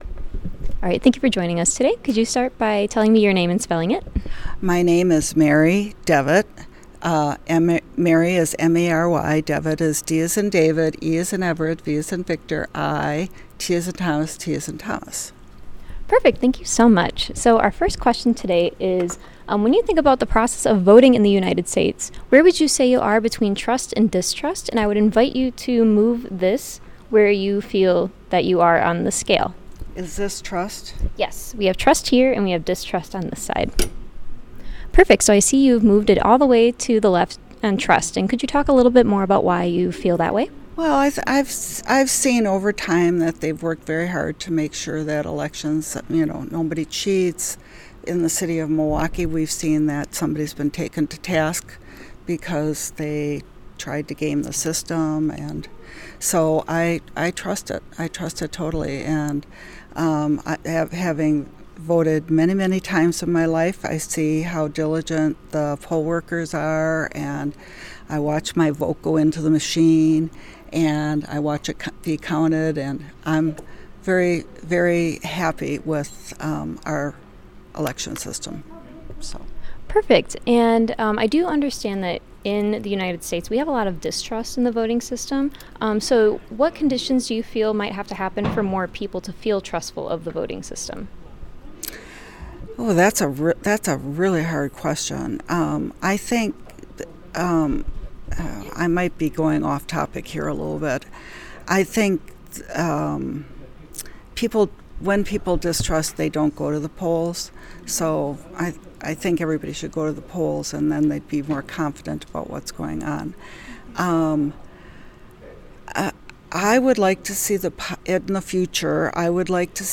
Location UWM Central Library